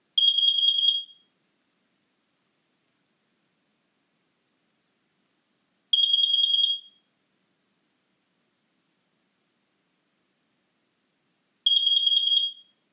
• Kidde X10.2 Alarm:
Nach Ablauf dieser 4 Minuten wird das Alarmintervall auf 4 laute Alarmtöne im 30-Sekunden-Takt gesenkt.
• Akustischer Alarm: ≥ 85 dB (in 3m)
kidde-x10.2-co-alarm.mp3